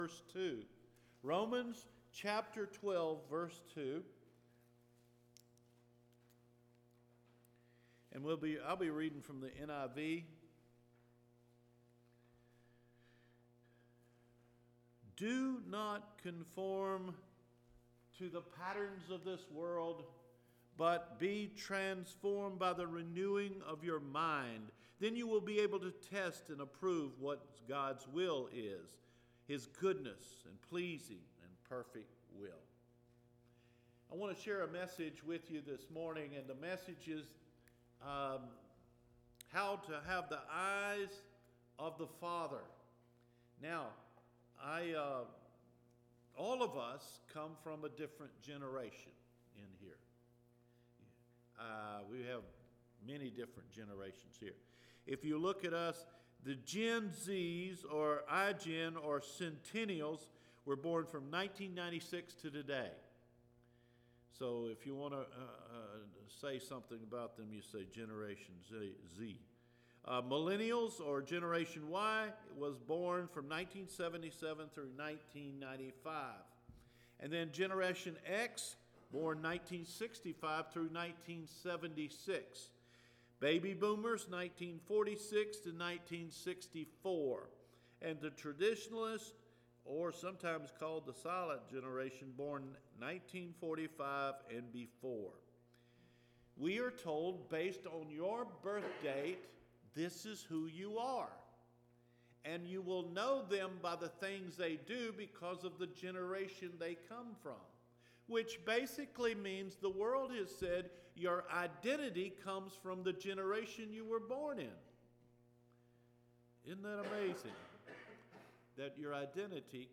With The Eyes of the Father – January 13 Sermon